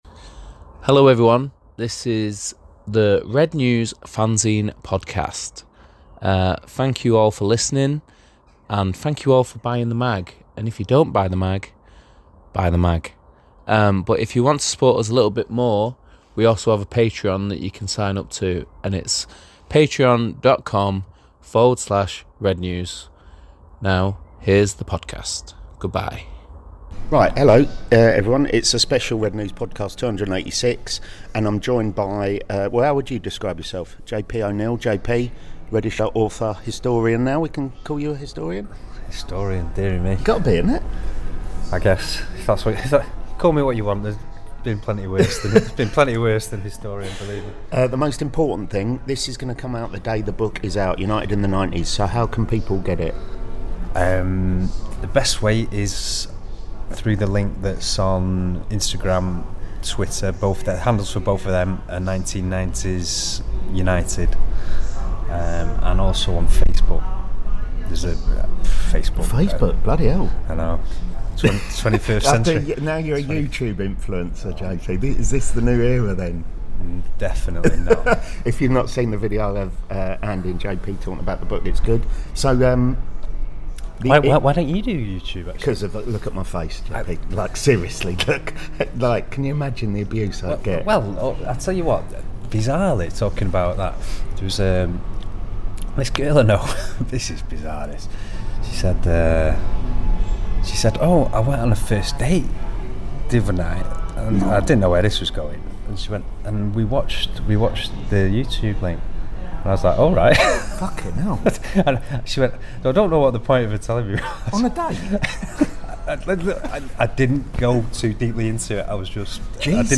The independent, satirical Manchester United supporters' fanzine - for adults only, contains expletives, talks MUFC, or not at times, as we talk about this significant new tome on the Reds.